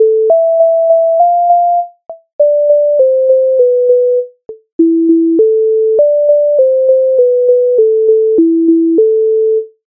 MIDI файл завантажено в тональності a-moll
На вулиці скрипка грає Українська народна пісня зі збірки Михайловської
Ukrainska_narodna_pisnia_Na_vulytsi_skrypka_hraie.mp3